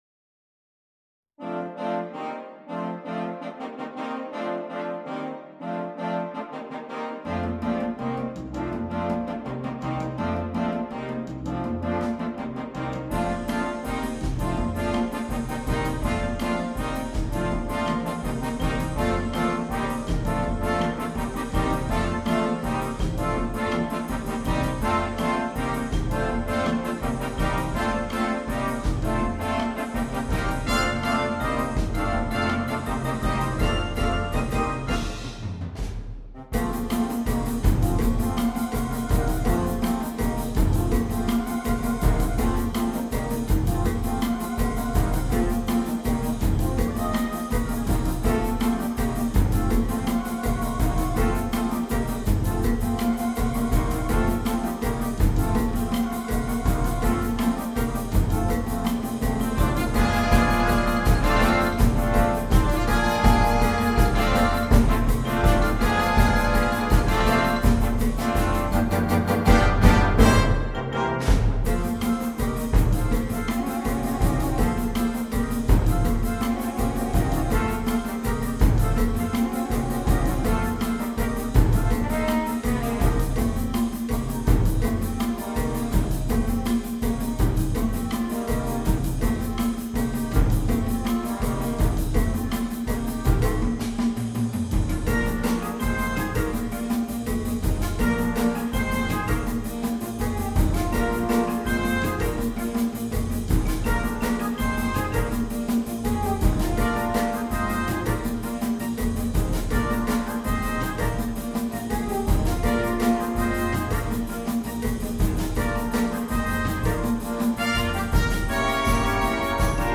для духового оркестра
& Xilophone, Drums, Conga, Tambourine & Cowbell, Contrabass.